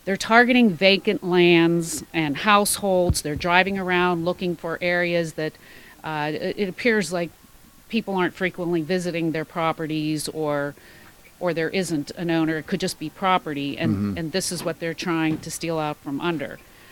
In an interview on Indiana in the Morning on Tuesday, Indiana County Recorder of Deeds Maria Jack said that first of this string of scams was reported on November 21st.